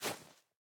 Minecraft Version Minecraft Version snapshot Latest Release | Latest Snapshot snapshot / assets / minecraft / sounds / block / powder_snow / break3.ogg Compare With Compare With Latest Release | Latest Snapshot